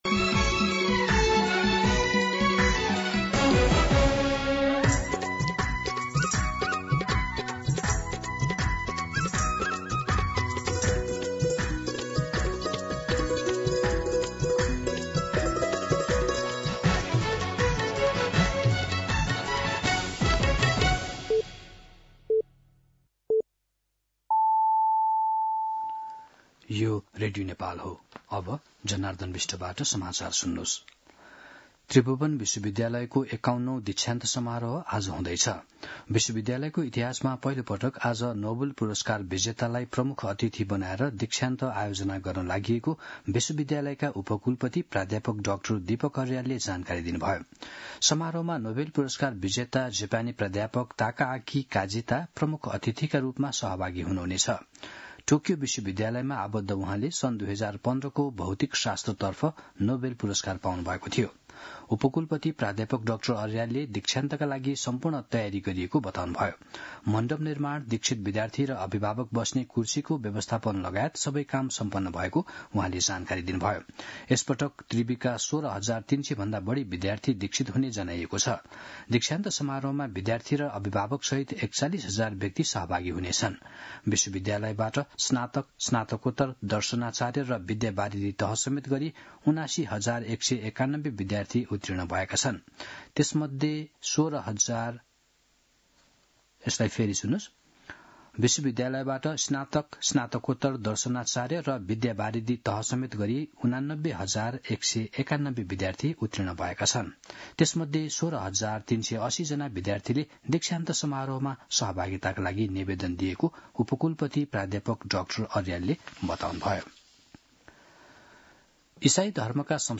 मध्यान्ह १२ बजेको नेपाली समाचार : १० पुष , २०८२
12-pm-Nepali-News-3.mp3